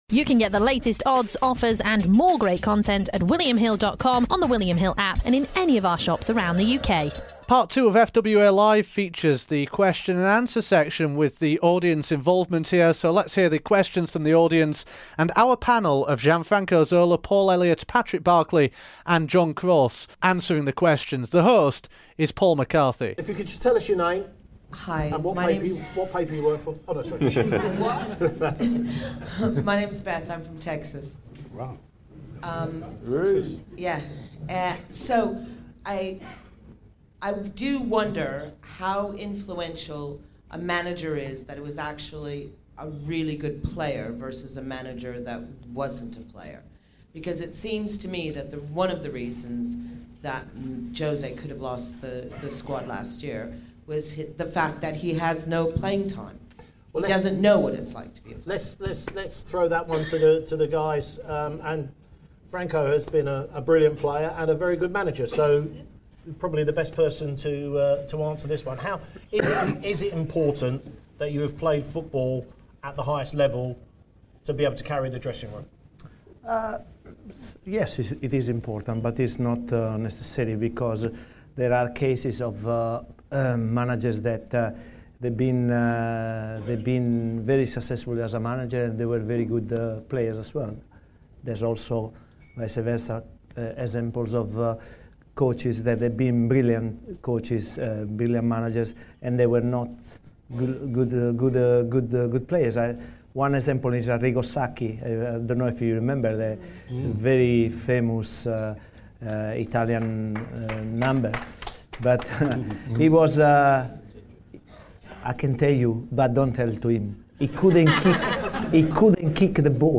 FWA Live hit Chelsea with a stellar panel discussing all tings football.